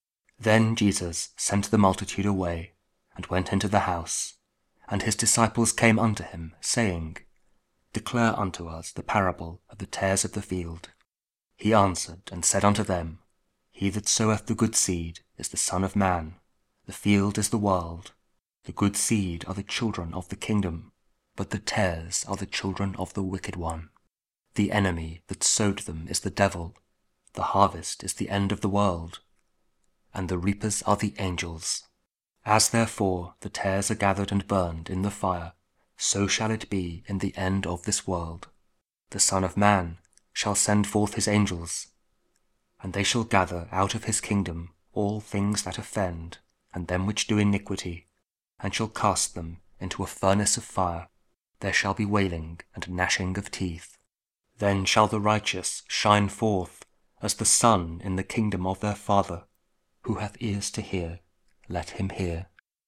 Matthew 13: 36-43 – Week 17 Ordinary Time, Tuesday (King James Audio Bible KJV, Spoken Word)